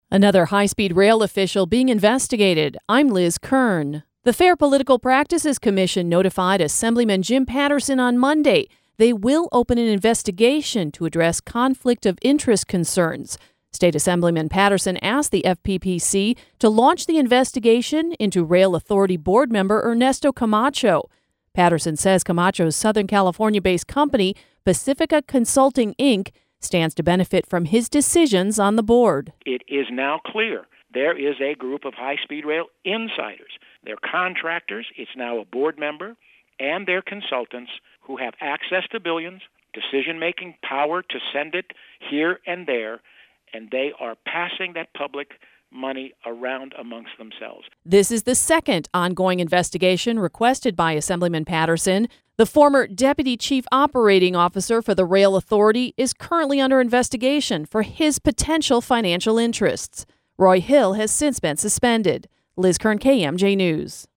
LK-WEB-HSR-INVESTIGATION.mp3